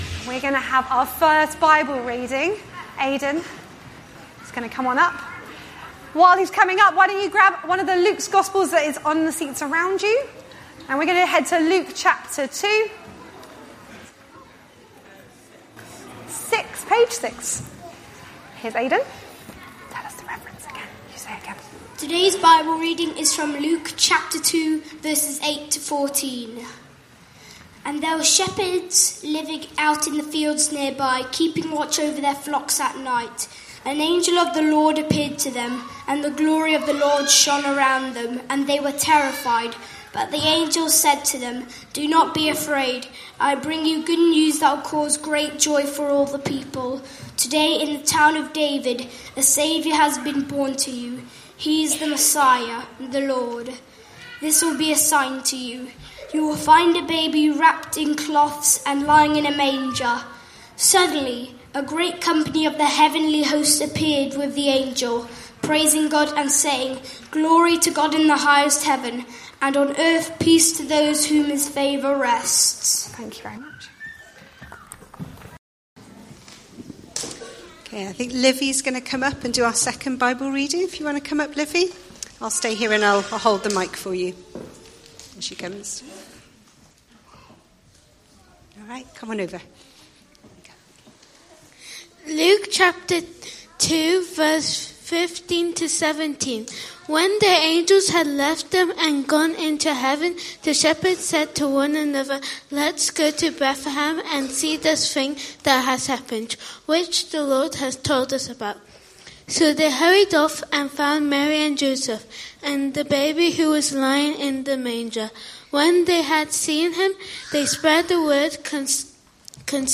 Luke 2:8-17; 15 December 2024, Afternoon Service. Sermon Series